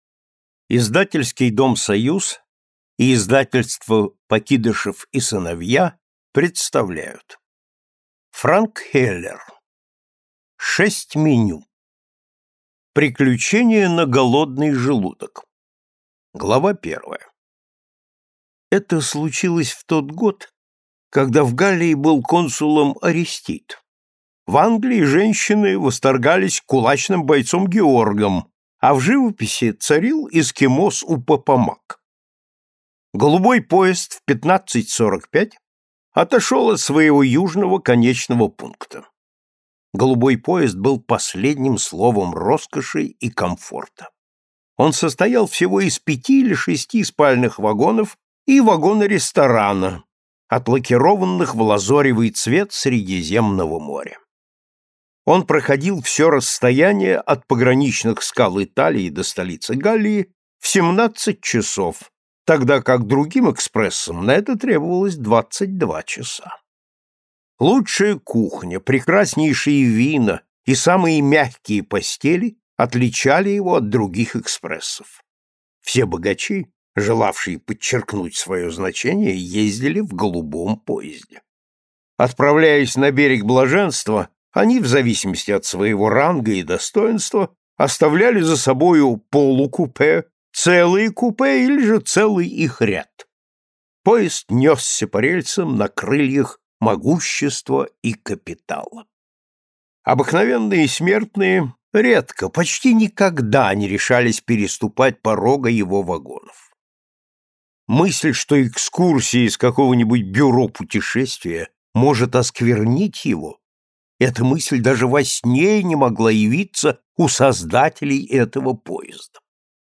Aудиокнига Шесть меню